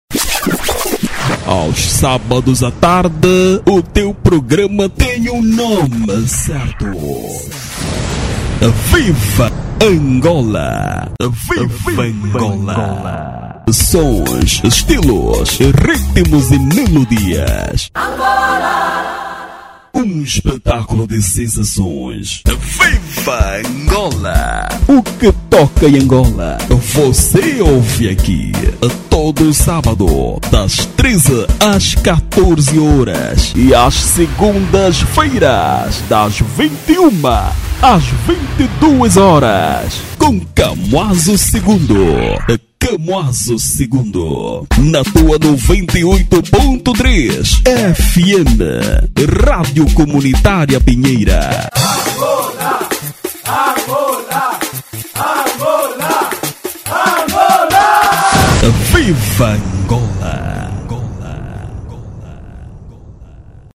Masculino
Rádio - Programa